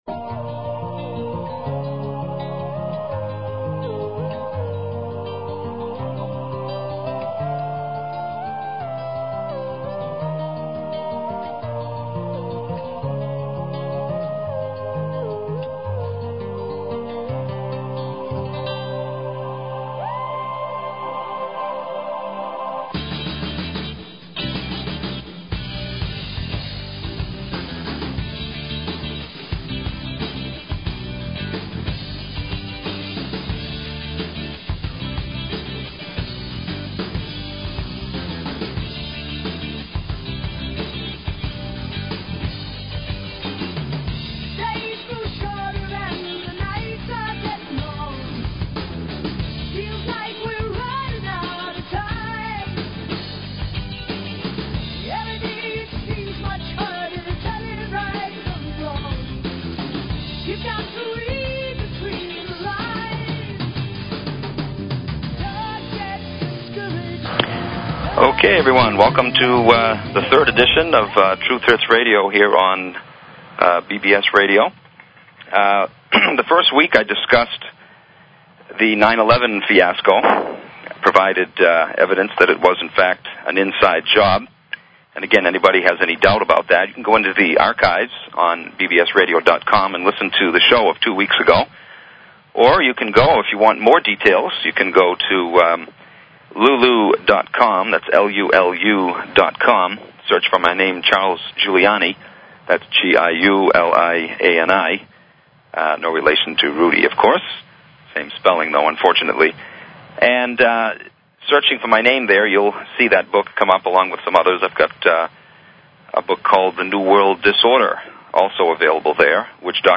Talk Show Episode, Audio Podcast, Truth_Hertz_Radio and Courtesy of BBS Radio on , show guests , about , categorized as